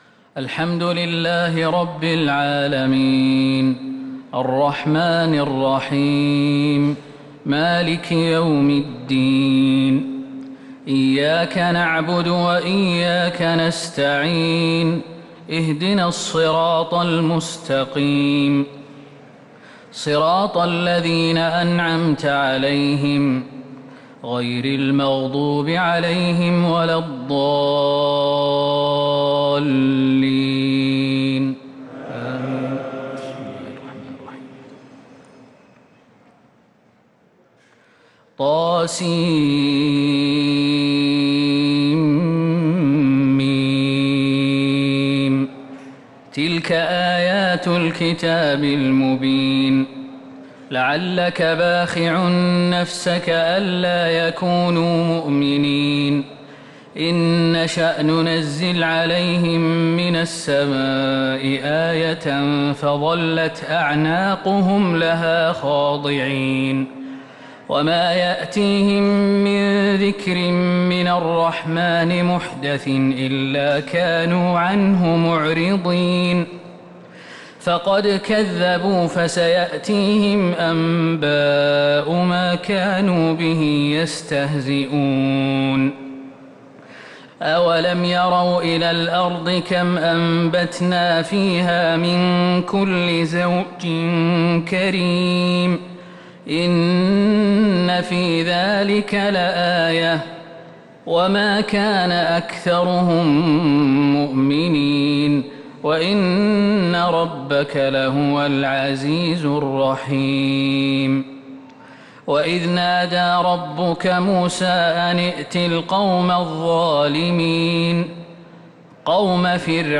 تراويح ليلة 23 رمضان 1443 هـ من سورة الشعراء (1-207) | Taraweeh prayer 23St night Ramadan 1443H from surah Ash-Shuara > تراويح الحرم النبوي عام 1443 🕌 > التراويح - تلاوات الحرمين